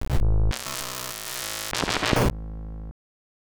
Glitch FX 10.wav